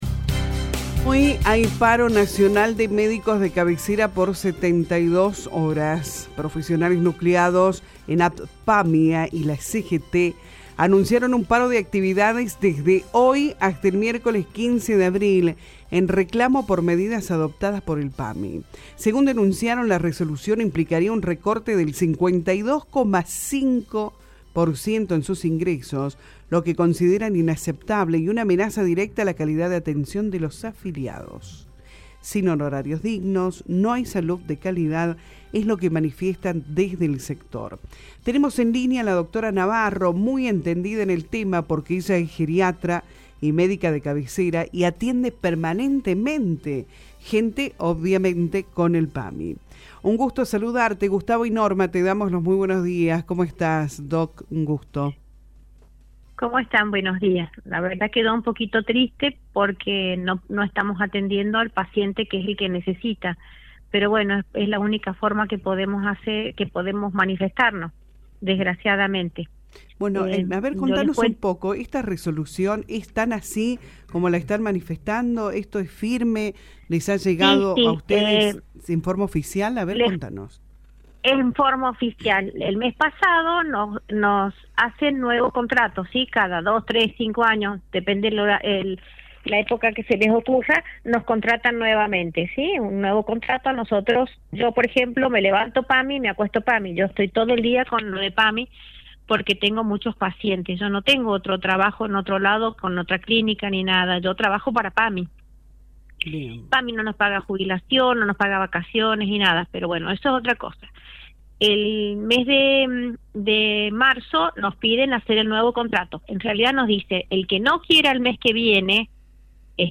El análisis técnico en Radio La Bomba